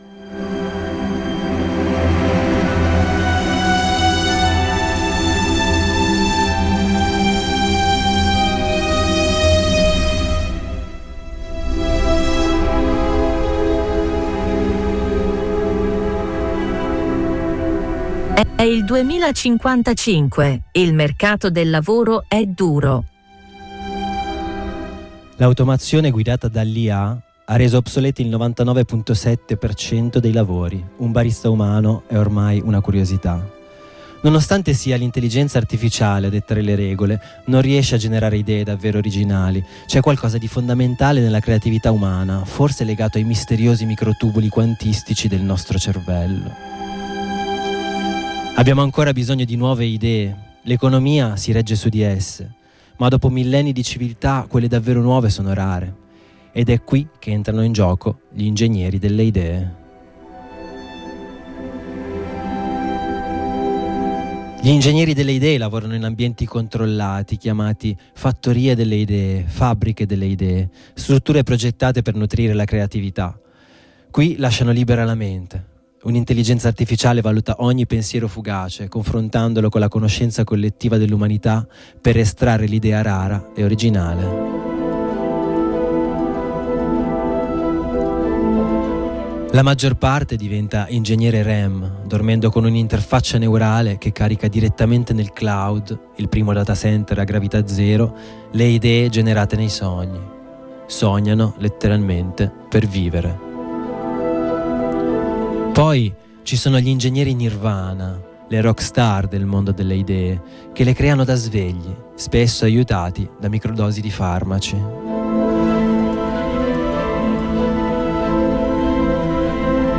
Lettura delirante della traduzione del racconto How To Get A Common Lisp Job In 2055 di Sebastian Carlos ( leggibile qui per intero ).
lettura_2055_clisp.mp3